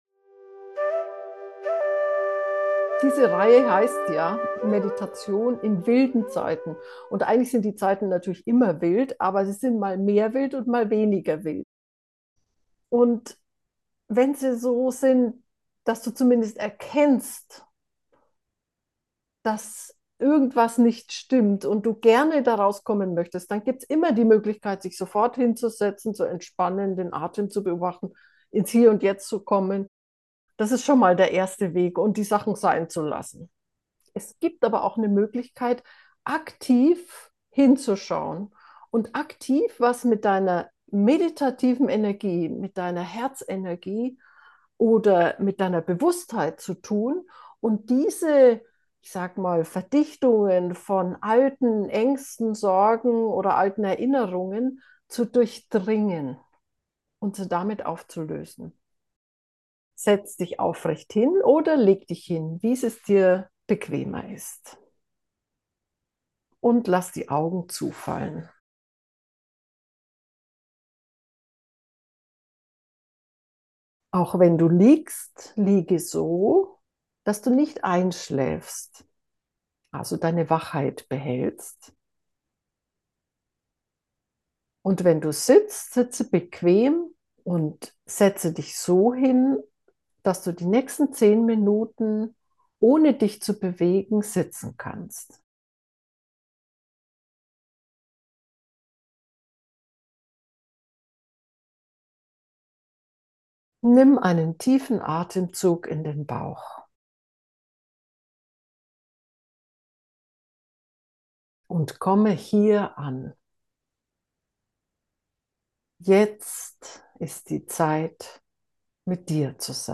Folge 202: Erfahre die Magie von Bewusstheit: geführte Herzmeditation zur Befreiung in wilden Zeiten - FindYourNose